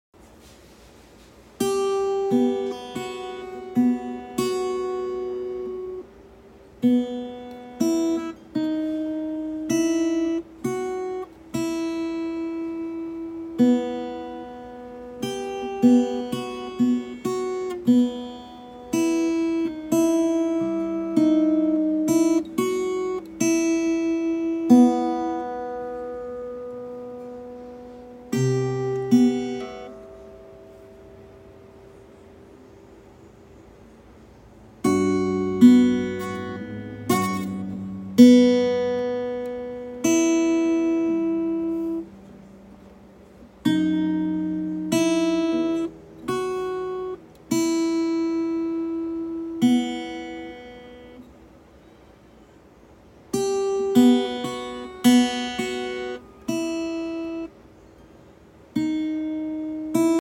Guitar Cover